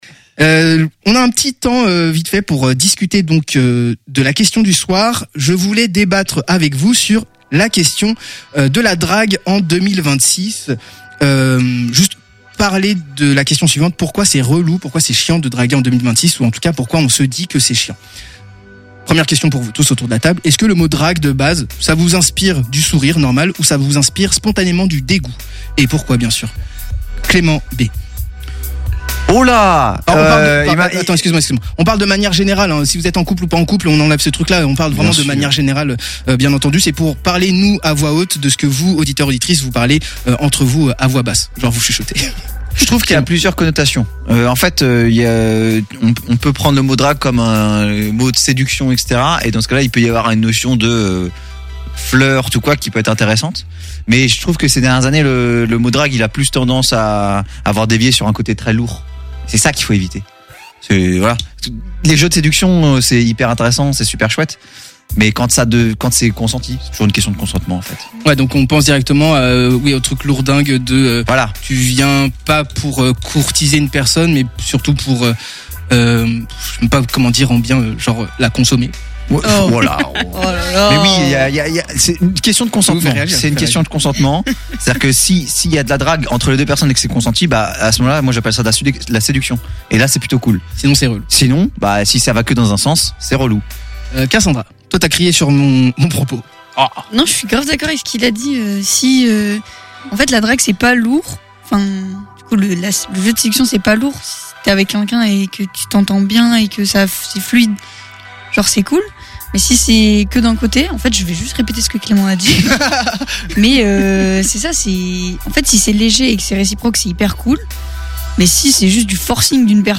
Débat - G!